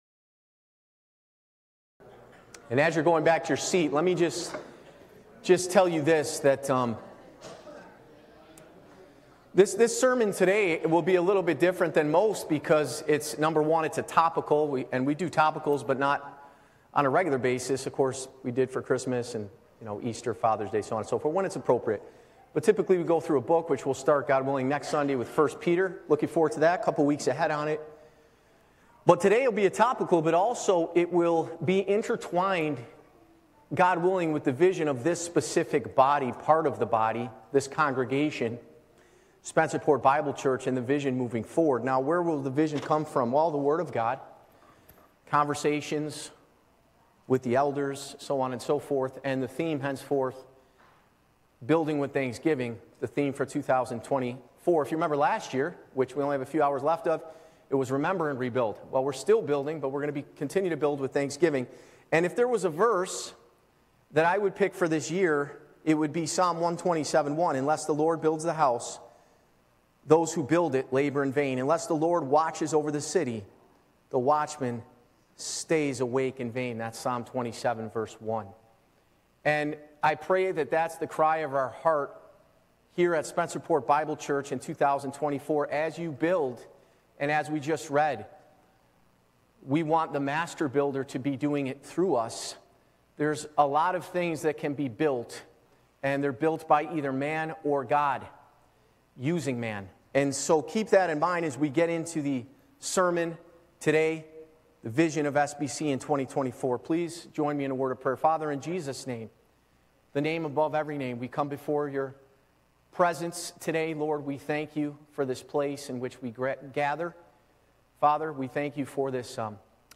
Church Location: Spencerport Bible Church
Live Recording